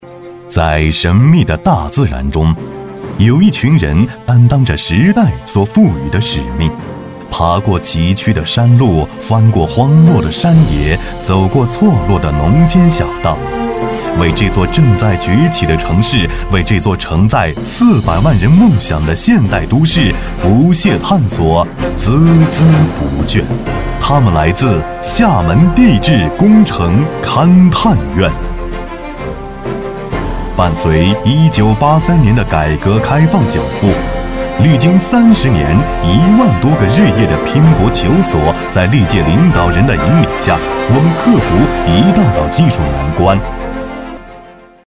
地质工程男21号
轻松自然 企业宣传配音
大气明亮，自然质感男音，擅长旁白讲述、专题、科技宣传片、mg等题材。